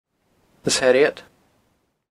Gaelic name: Lios Harriet Name in Original Source: Lios Harriet English meaning: Harriet's garden Placename feature: House site/Garden ground Notes: This place name has changed several times over the years, and is nowadays pronounced as Less Herriot.